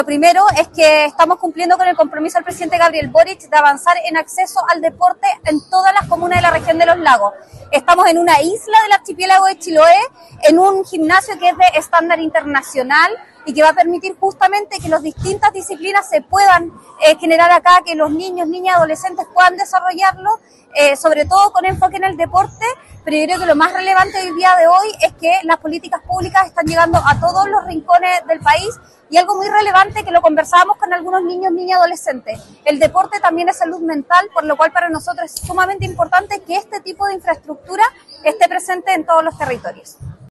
Para Giovanna Moreira, Delegada Presidencial Regional de Los Lagos, este proyecto materializa un compromiso presidencial: